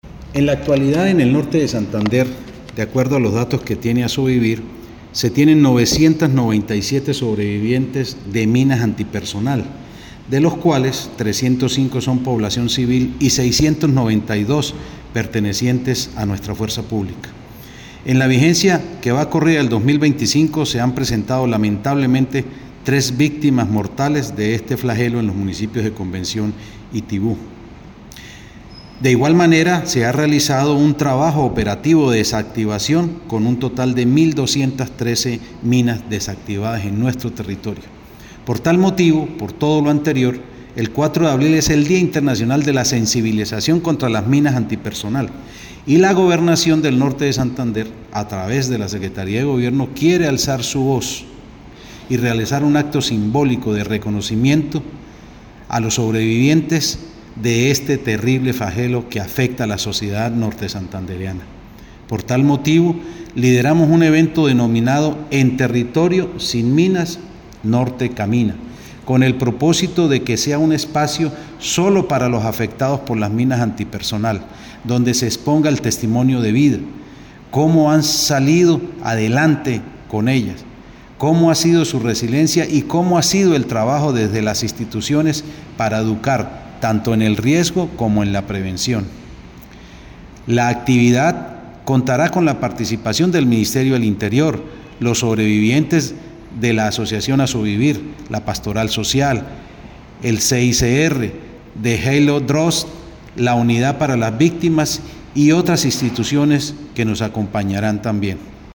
Audio-de-Jhonny-Penaranda-secretario-de-Gobierno.mp3